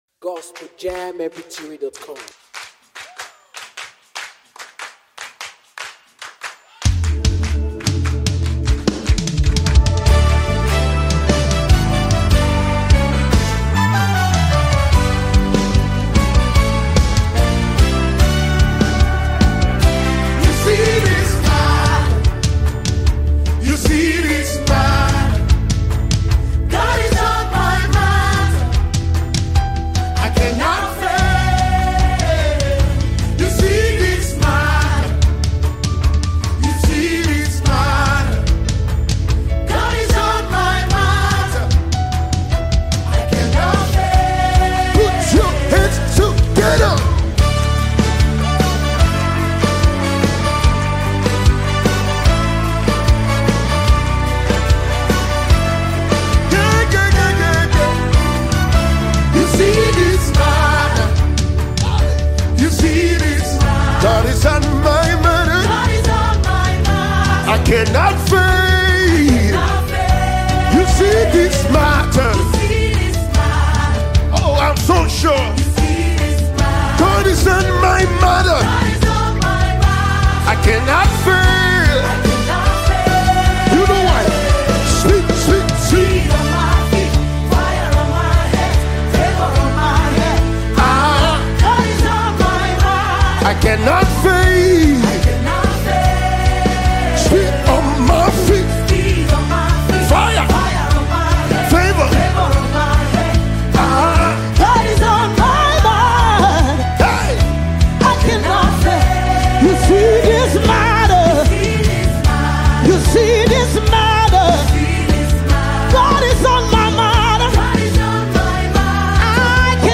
Famous Gospel Musicians